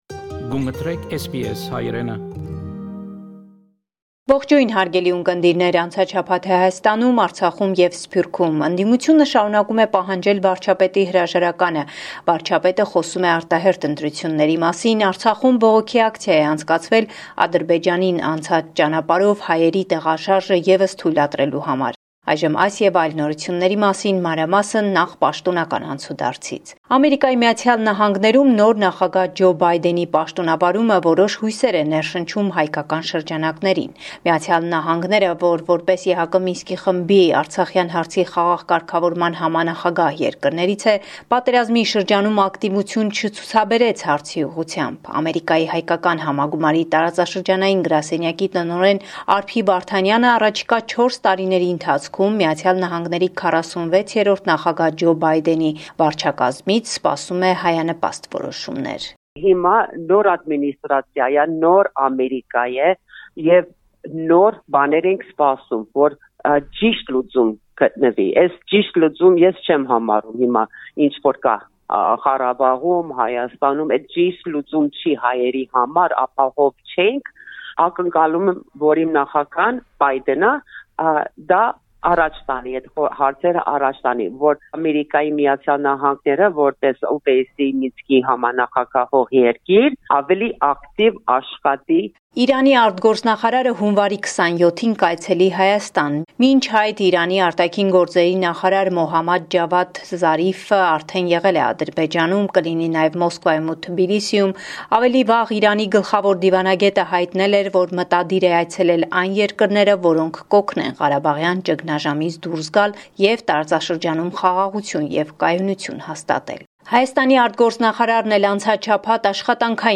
Latest News from Armenia– 26 January 2021
News from Armenia, Artsakh and the Diaspora from our reporter